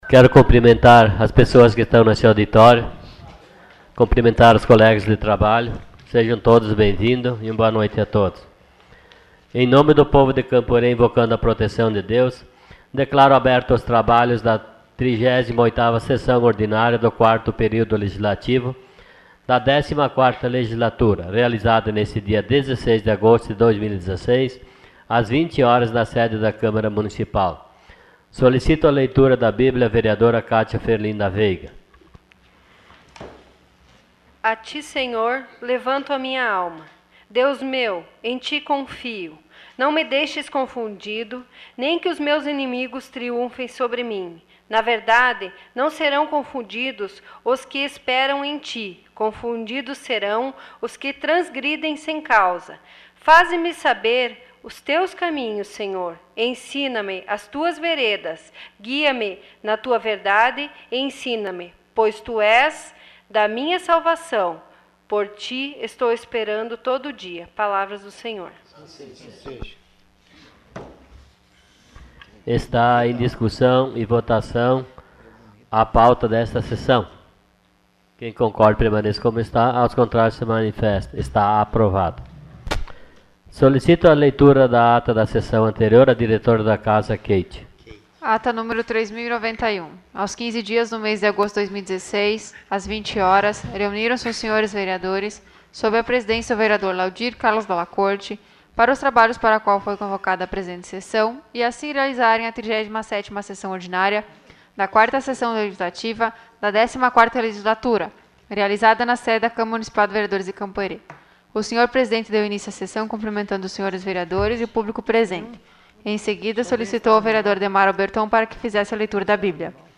Sessão Ordinária dia 16 de agosto de 2016.